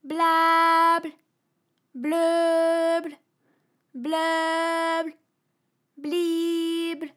ALYS-DB-001-FRA - First, previously private, UTAU French vocal library of ALYS
blablebleublibl.wav